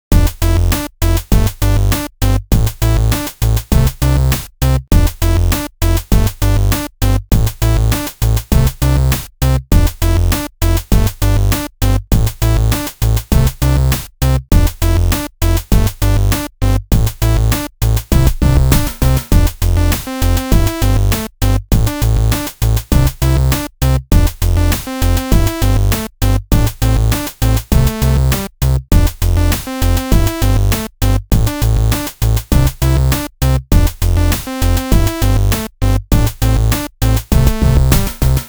An attempt at making some chiptune music for a game that ended up not being used.The Bosca Ceoil project file is included in the downloads, in case someone more musically talented wants to do something with it.